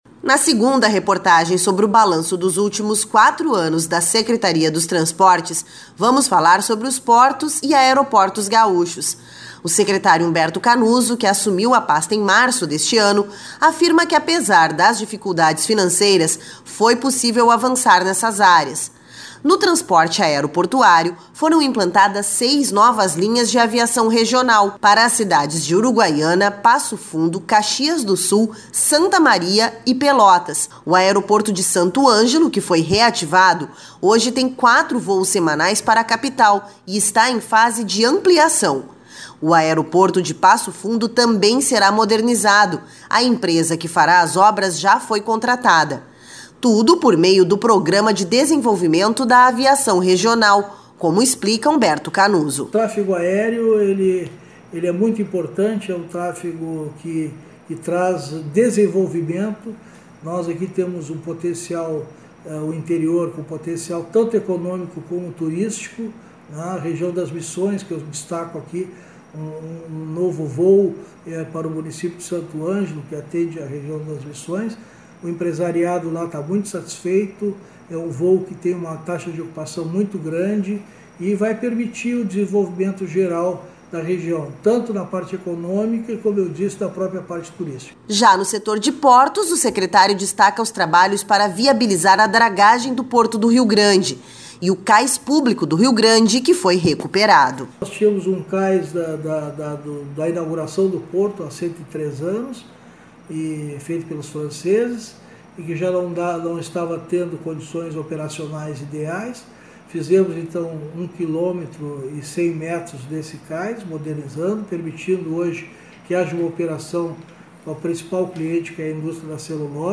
Nesta entrevista de balanço de gestão, Humberto Canuso destaca os desafios, as dificuldades, mas sobretudo as realizações conseguidas pela pasta nos últimos quatro anos.